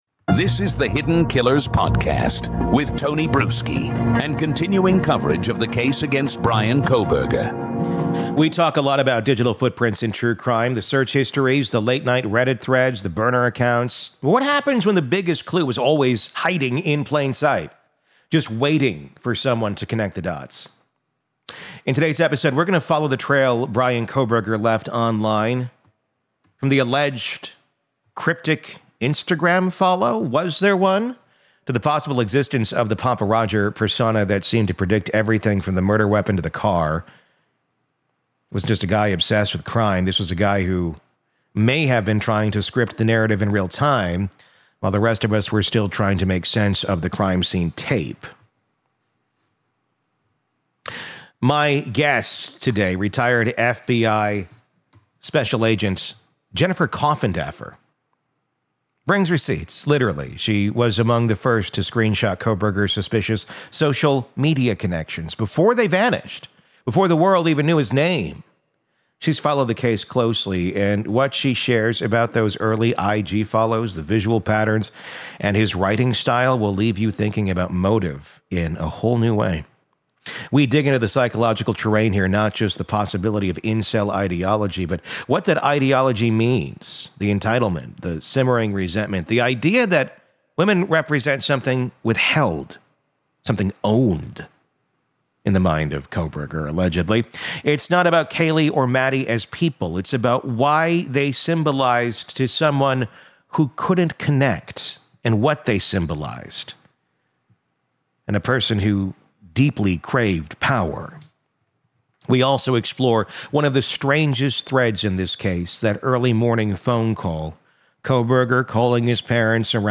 It’s a dark, speculative discussion—but one we need to have, before the publishing world gets ahead of accountability.